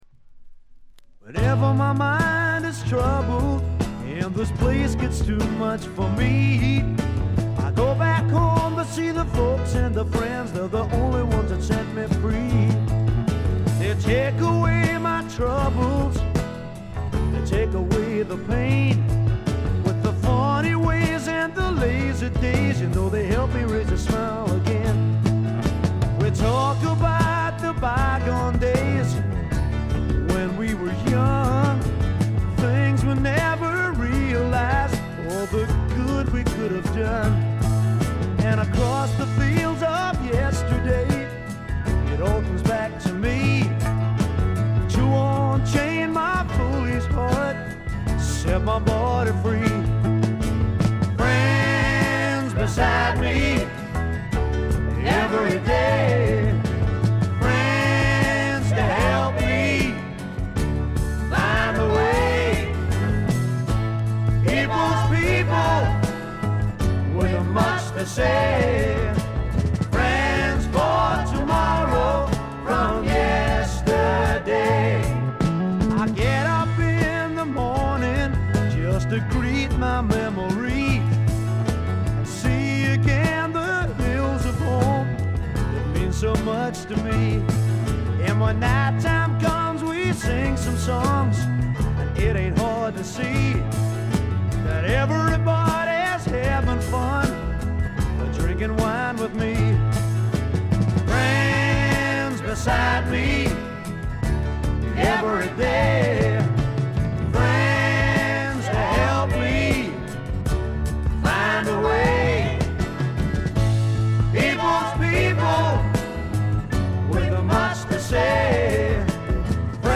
部分試聴ですが、静音部での軽微なバックグラウンドノイズ程度。
なにはともあれ哀愁の英国スワンプ／英国フォークロック基本中の基本です。
試聴曲は現品からの取り込み音源です。